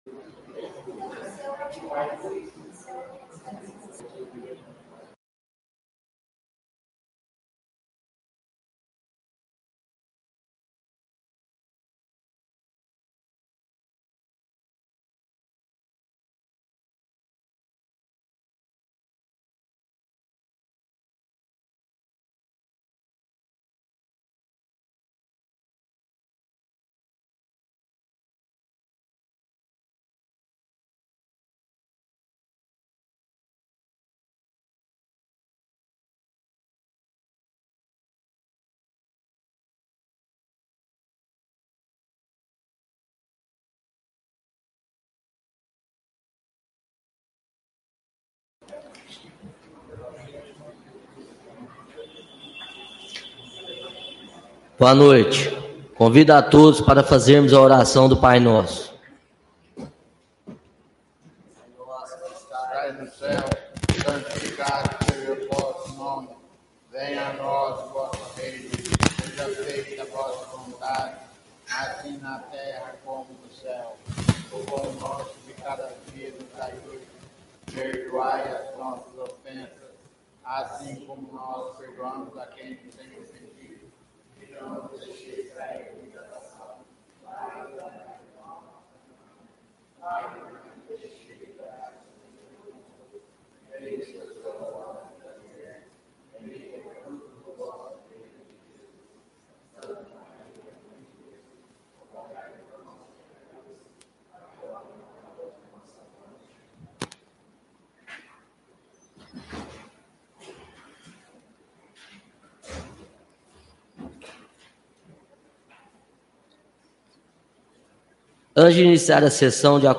Áudio da sessão ordinária de 09/10/2023